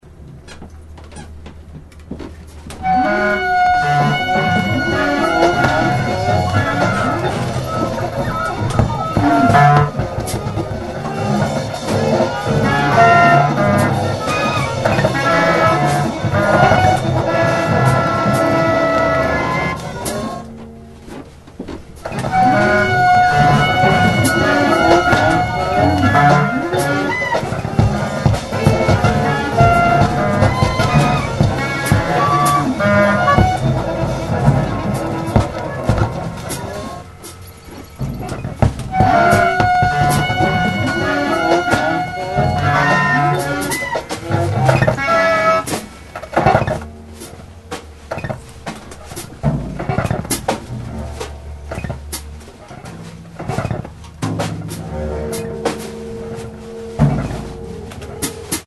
Recorded August 1, 1999 at The Nervous Center, Chicago.
Sampler, Turntables, Tapes
20 String Steel Bass
Percussion